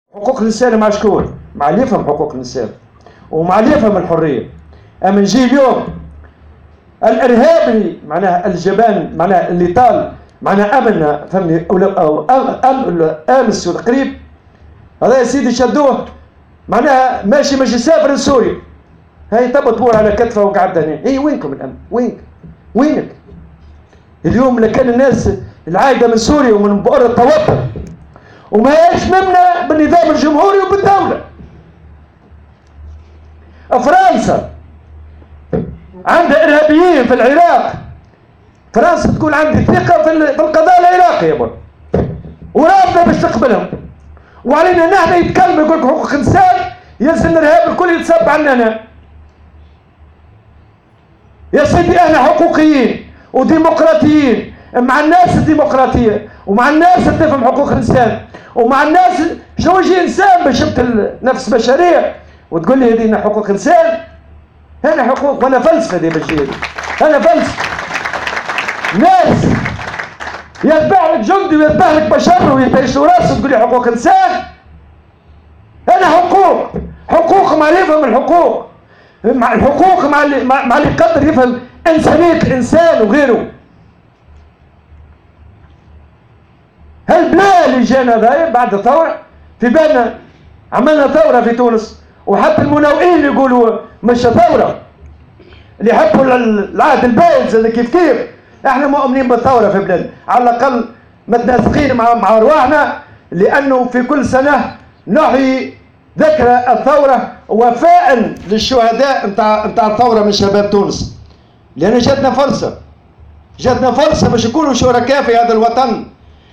وأشار الطبوبي، في كلمة ألقاها لدى انعقاد أشغال المؤتمر العادي للجامعة العامة للتخطيط والمالية بالحمامات، اليوم السبت، إلى أن دولة مثل فرنسا ترفض استقبال مواطنيها المنتمين لتنظيمات إرهابية في بؤر التوتر مستهجنا مطالبات بعض الحقوقيين في تونس بالسماح للإرهابيين التونسيين بالعودة إلى تونس.